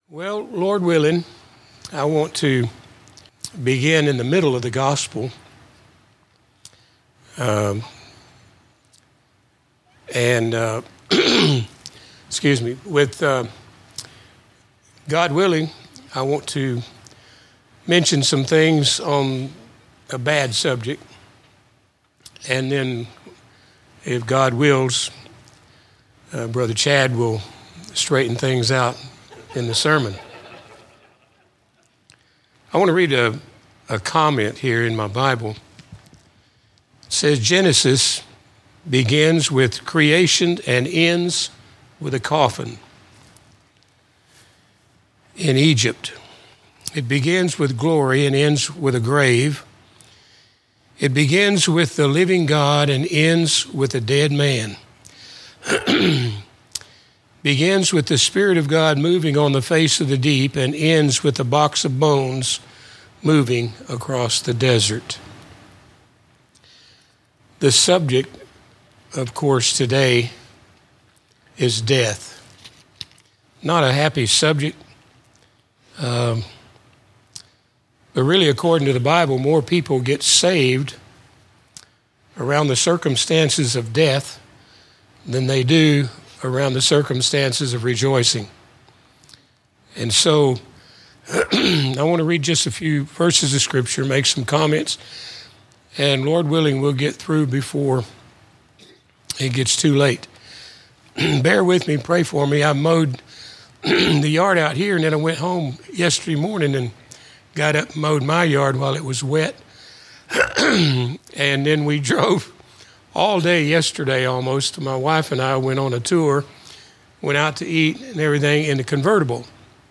A message from the series "General Teaching."